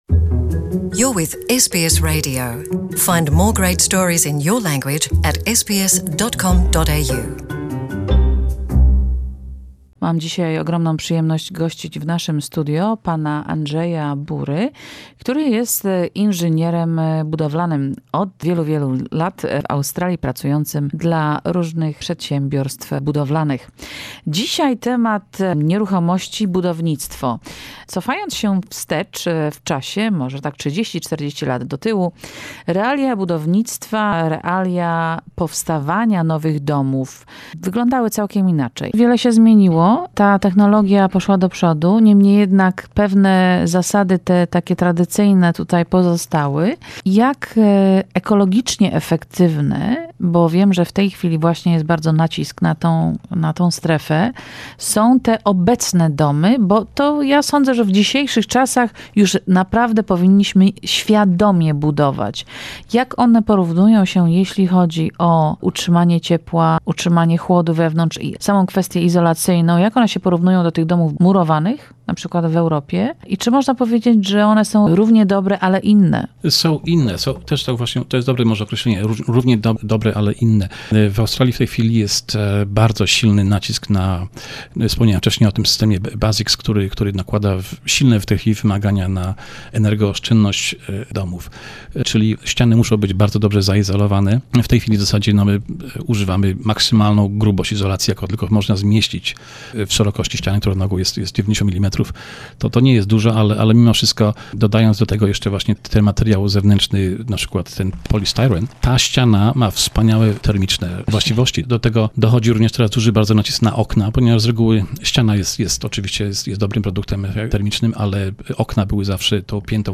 Reduce your energy consumption and increase your property value to name only few. For more listen to interview with an expert